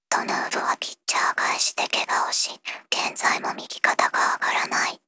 voicevox-voice-corpus / ROHAN-corpus /ナースロボ＿タイプＴ_内緒話 /ROHAN4600_0015.wav